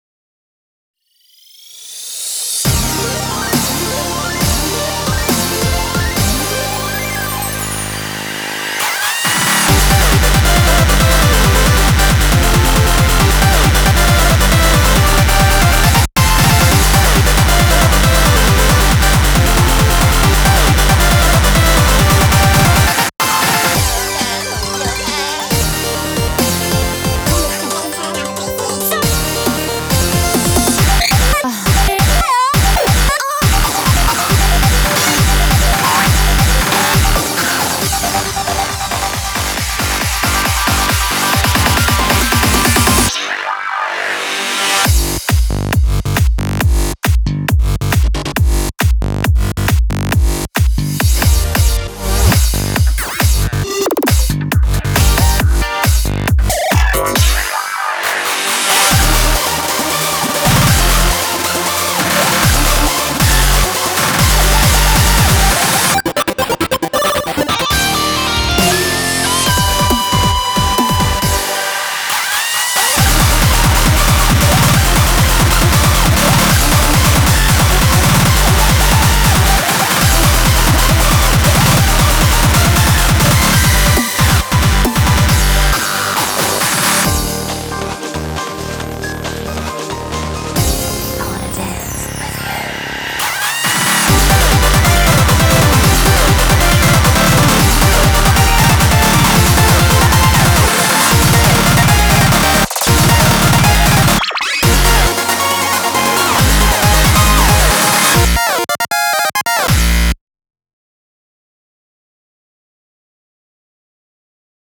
BPM137-273
Audio QualityPerfect (High Quality)
Genre: CHAOTIC SPEEDCORE.